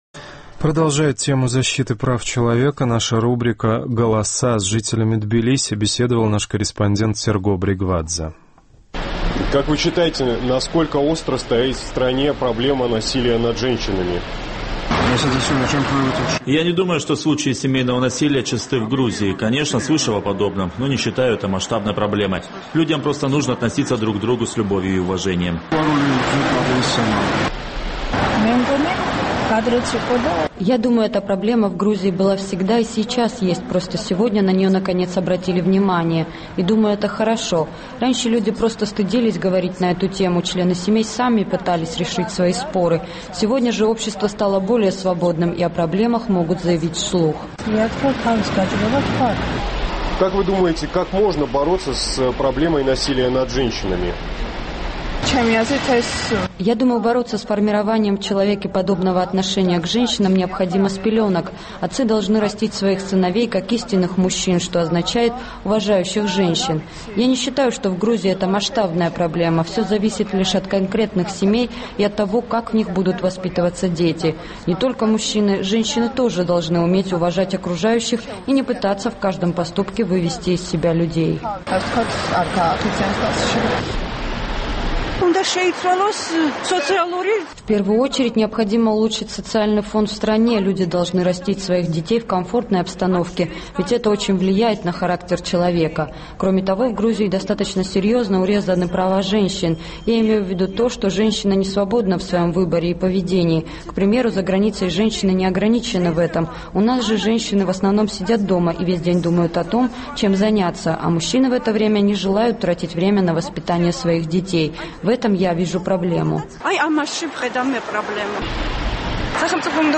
НПО и общественность Грузии требуют от властей обратить больше внимания на проблему насилия над женщинами. Наш корреспондент поинтересовался у жителей грузинской столицы, насколько злободневен этот вопрос.